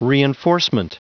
Prononciation du mot reinforcement en anglais (fichier audio)
reinforcement.wav